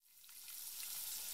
deep_fryer_immerse.ogg